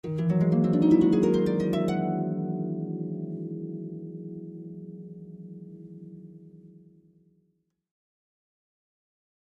Harp, Ascending Gliss, 7th Chords, Type 1